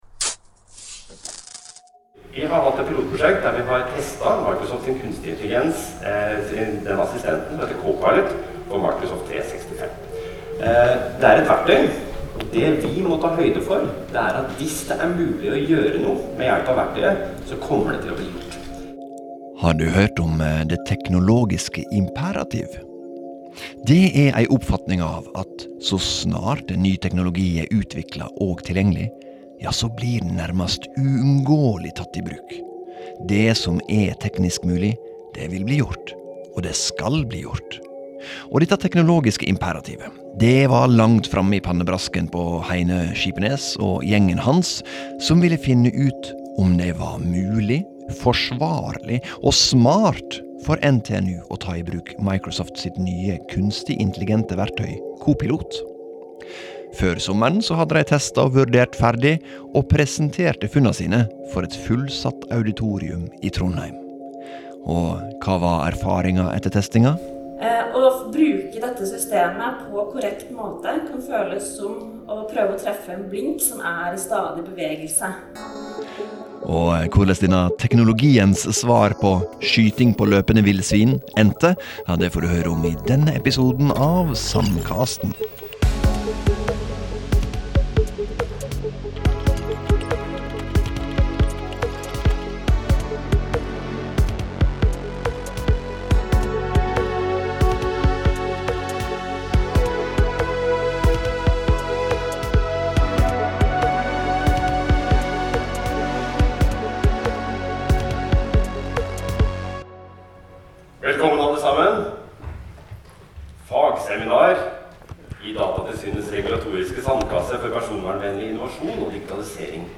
Opptaka til episoden er tekne i samband med eit fagseminar, der NTNU presenterte funn og ein rapport frå testinga.